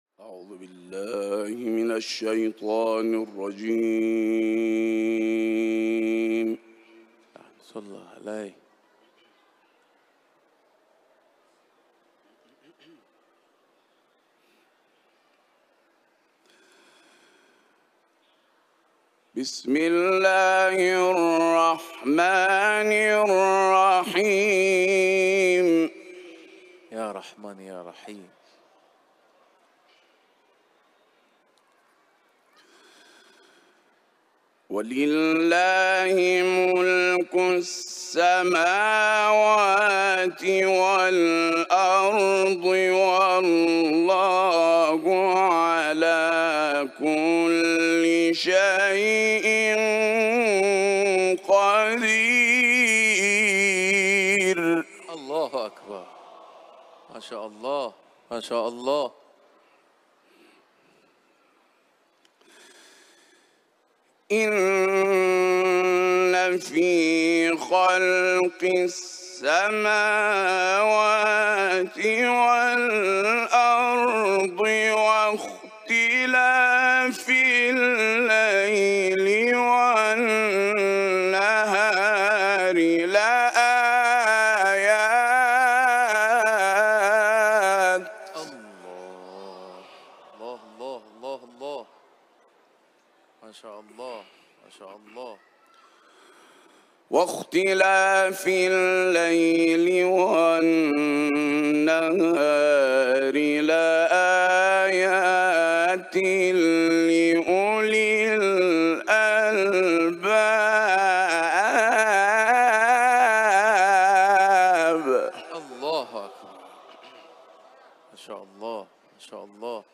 Lecture de la sourate Al Imran